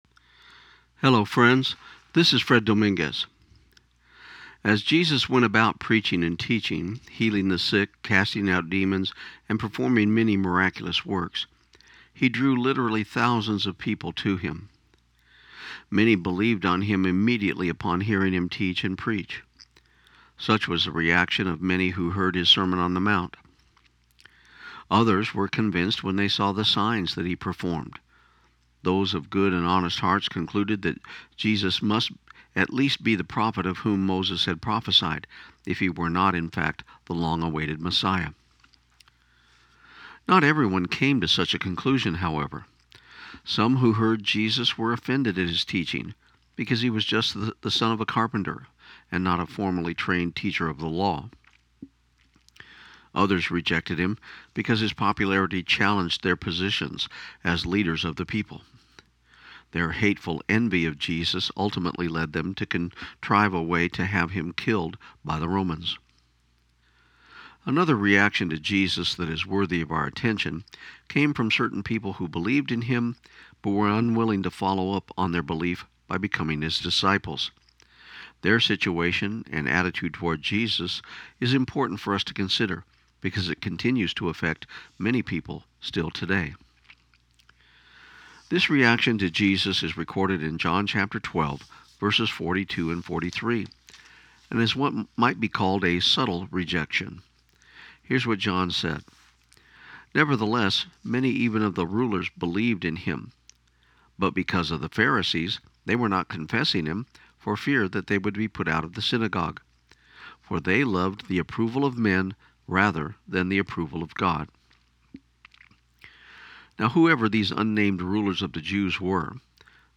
This program aired on KIUN 1400 AM in Pecos, TX on January 8, 2016.